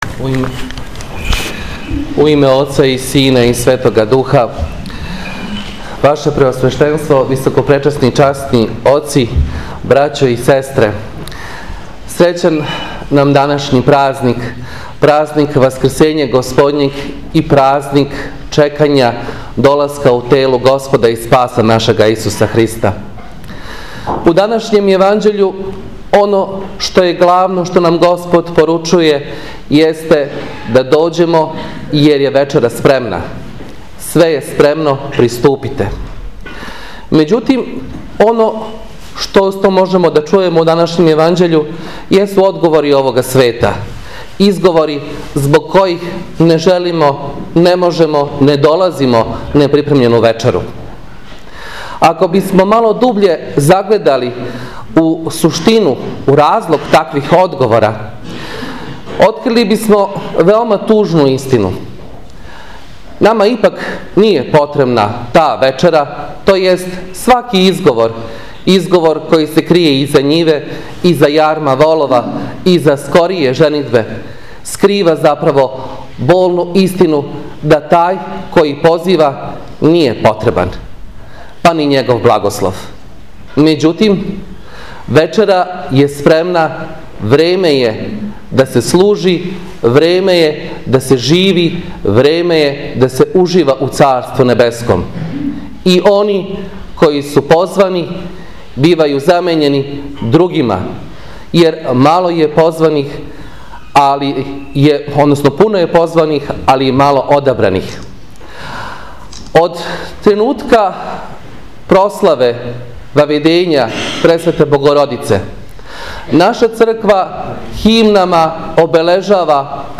НЕДЕЉА ПРАОТАЦА У СТАРОЈ ЦРКВИ У КРАГУЈЕВЦУ